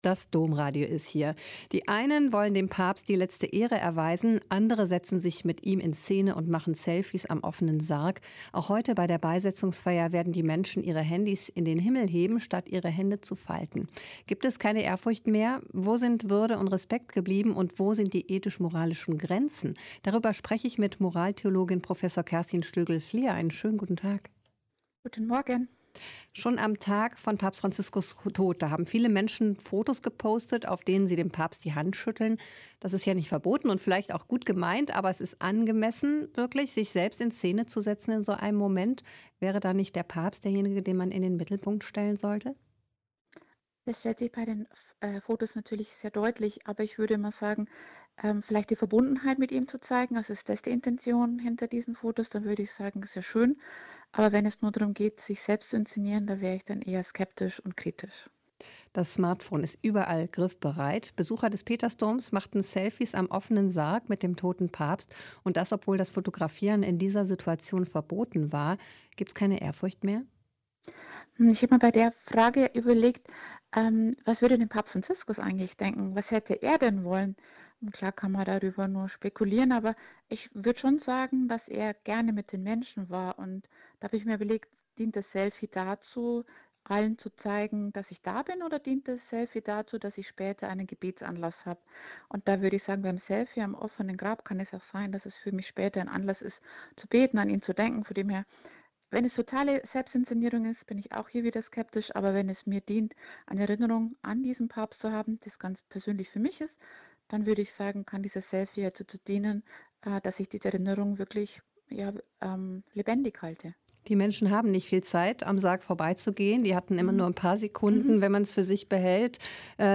Ein Interview mit Prof. Dr. Kerstin Schlögl-Flierl (Professorin für Moraltheologie an der Universität Augsburg und Mitglied im Deutschen Ethikrat)